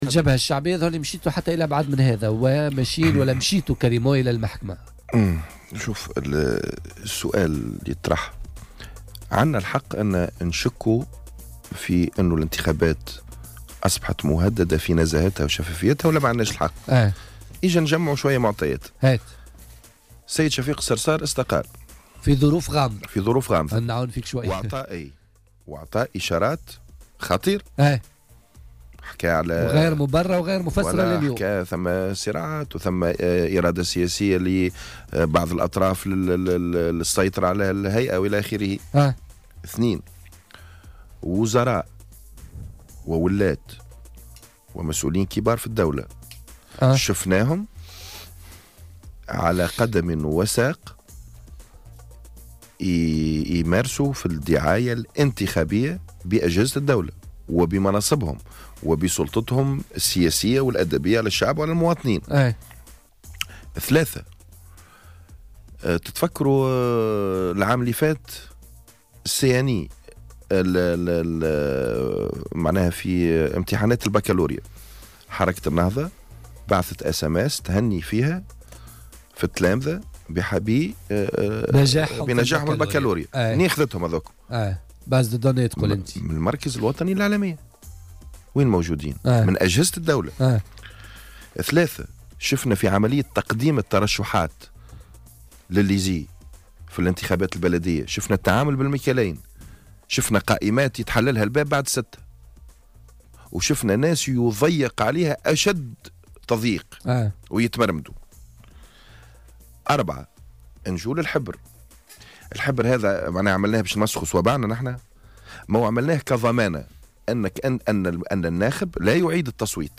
قال النائب عن الجبهة الشعبية بمجلس نواب الشعب أيمن العلوي ضيف بوليتيكا اليوم...